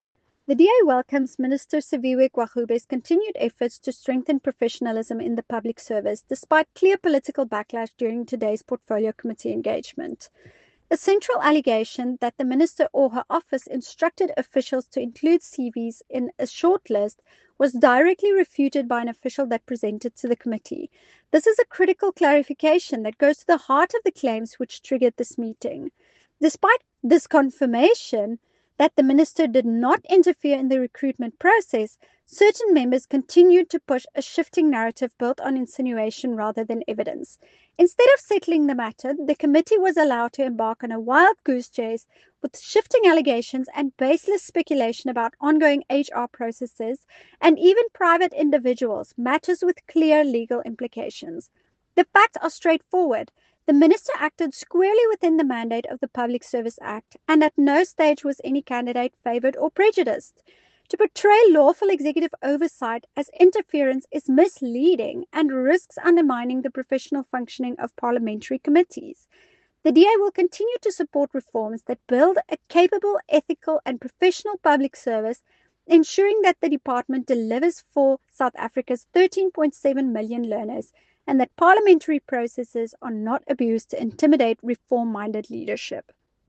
Afrikaans soundbites by Ciska Jordaan MP.